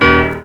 Index of /90_sSampleCDs/USB Soundscan vol.03 - Pure Electro [AKAI] 1CD/Partition E/04-PIANOS